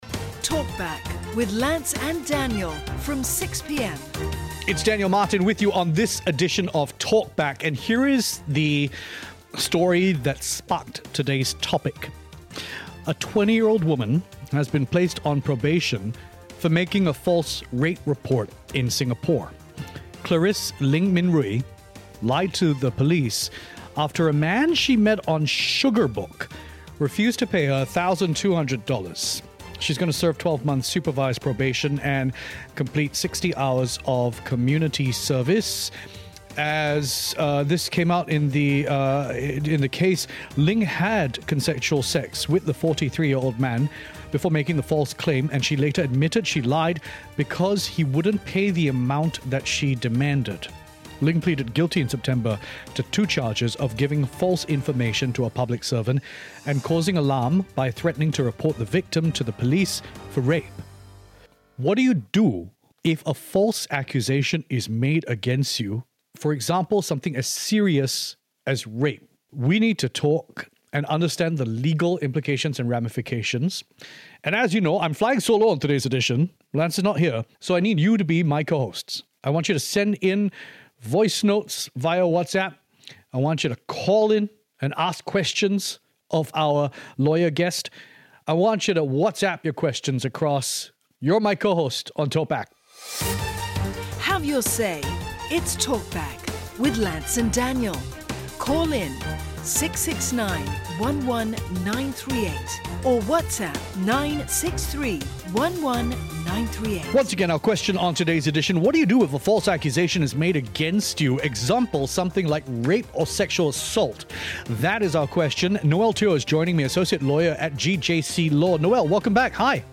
The discussion shed light on the broader impact of false reporting on the justice system and the reputational harm it can cause to innocent individuals.